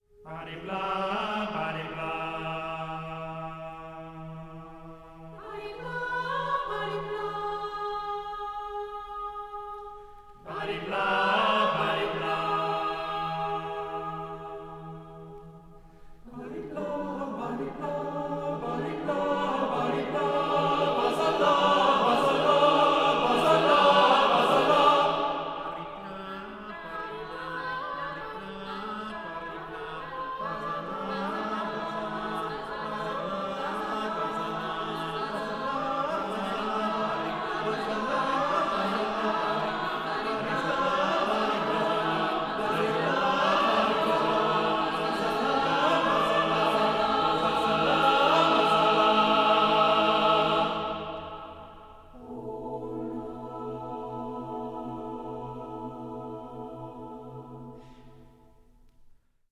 20-voice professional chamber choir